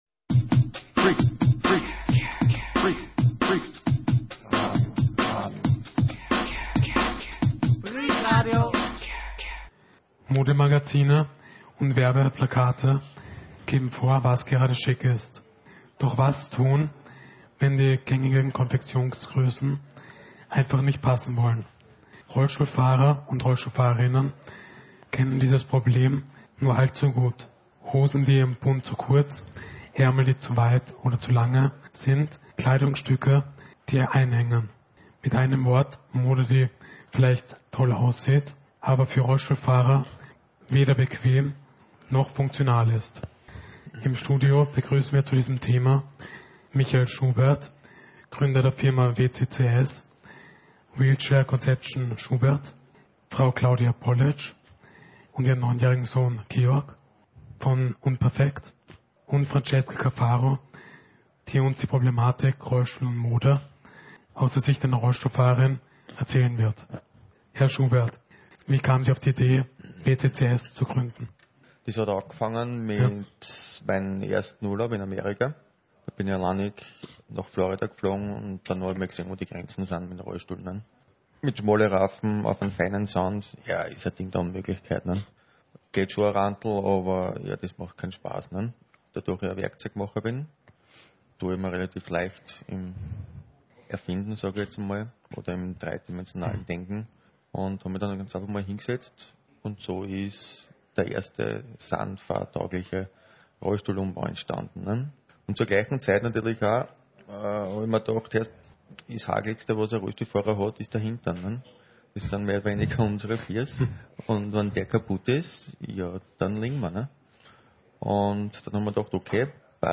Freak-Radio hat zu diesem Thema zwei Designer zum Studio-Gespräch geladen, die ihre Mode, ihre Ideen und ihr Firmenmotto vorstellen.